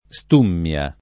stummia [ S t 2 mm L a ]